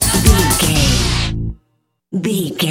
Dorian
Fast
drum machine
synthesiser
electric piano
Eurodance